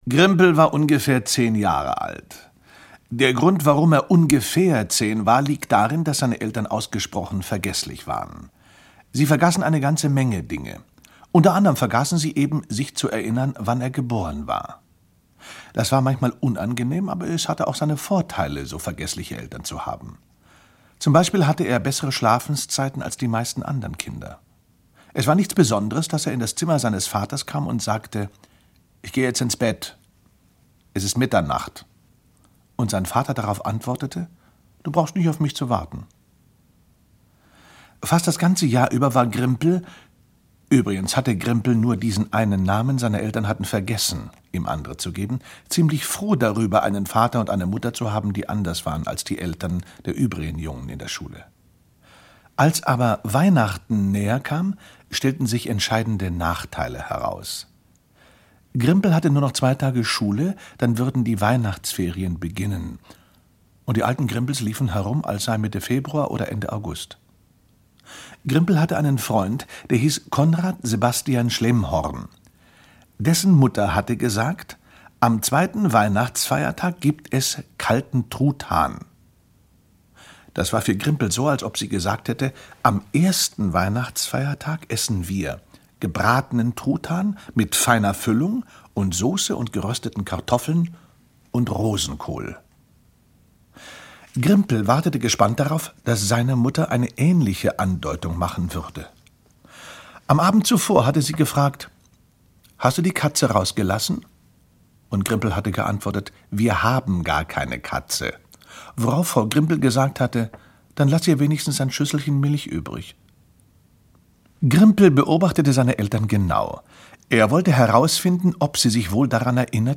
Grimpels superhöchstbestes Weihnachten ~ Hörspiele, Geschichten und Märchen für Kinder | Mikado Podcast